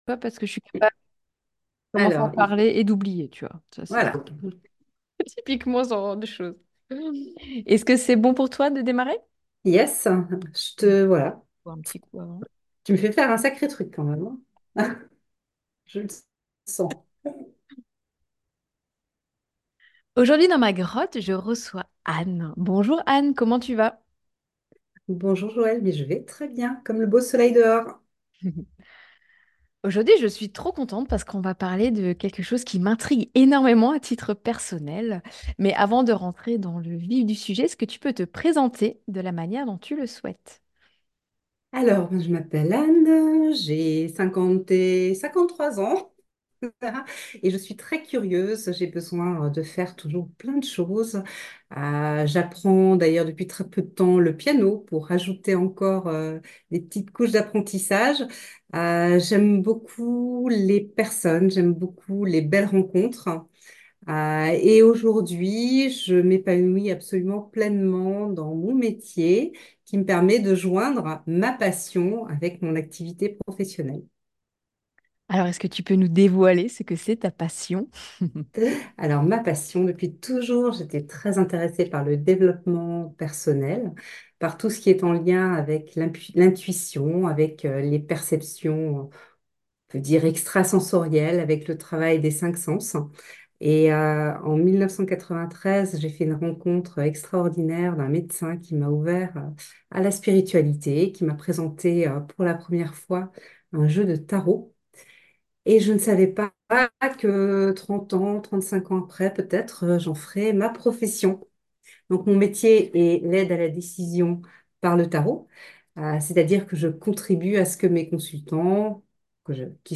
* *(à l’exclusion des questions relevant du domaine médical) // Découvrez mon univers au travers d’une interview intimiste « Est-ce le bon moment pour moi ?